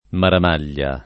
maramaglia [ maram # l’l’a ]